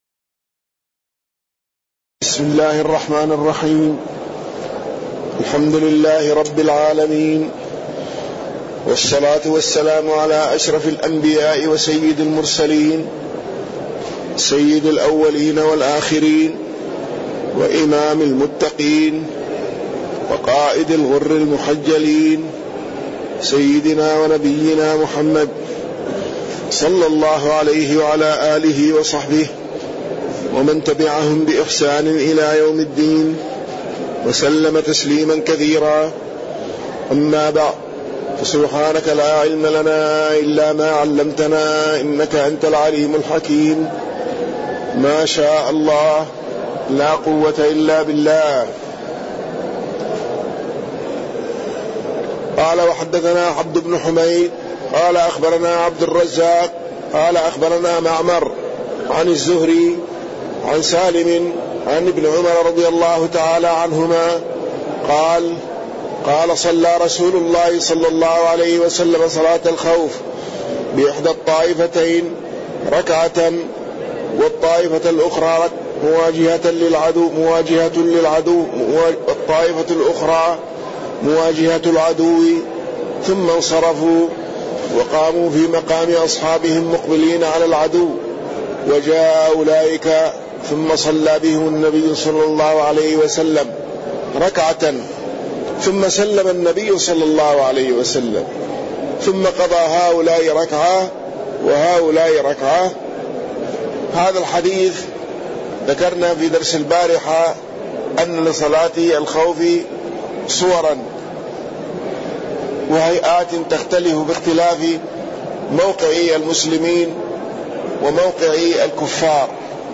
تاريخ النشر ١٨ جمادى الآخرة ١٤٣١ هـ المكان: المسجد النبوي الشيخ